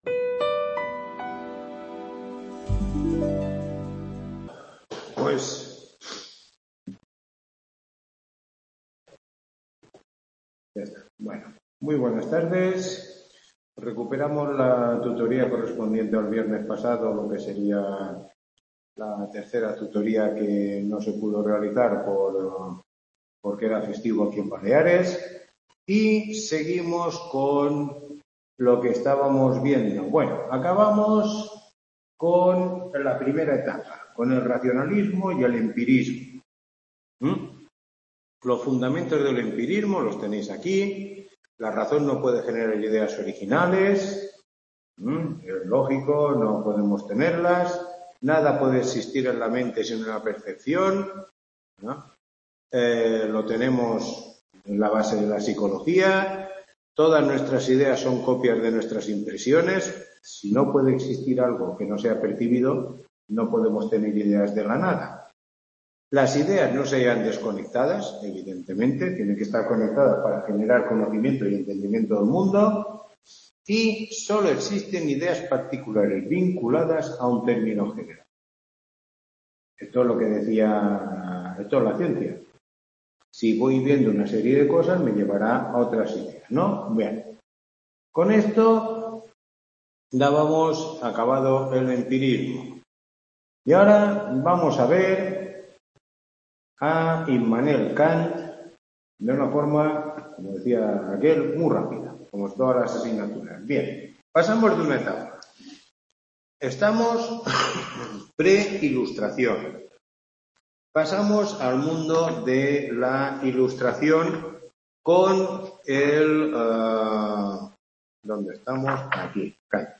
Tutoría 03